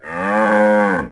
moo2.ogg